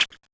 claw2.ogg